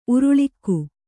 ♪ uruḷikku